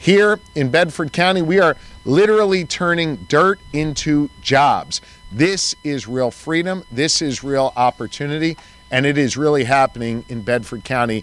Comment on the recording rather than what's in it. His appearance was at the 151-acre site for the Bedford County Business Park where $2.8 million dollars will be used to develop nine lots…